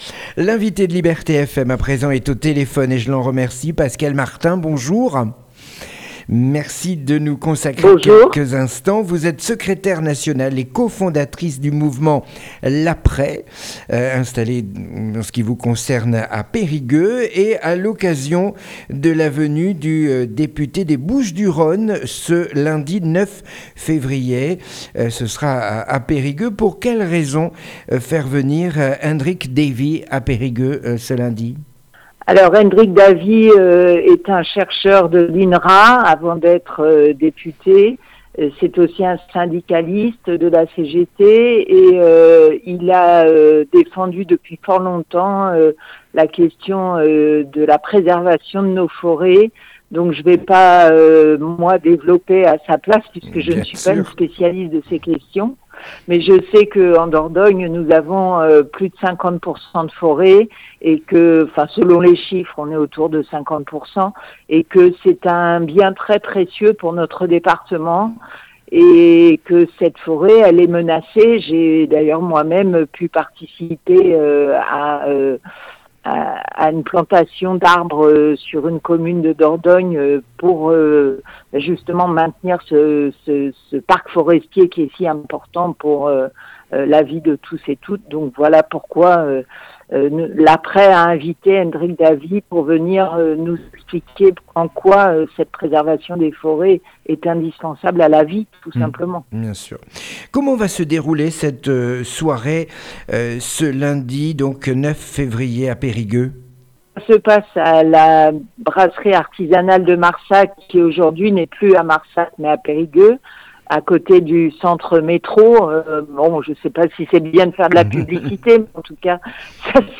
Avant la venue du député des BOUCHES DU RHÔNE à PERIGUEUX ce lundi 9 février ,PASCALE MARTIN co fondatrice de l'APRES est l'invitée de LIBERTE FM